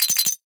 NOTIFICATION_Glass_01_mono.wav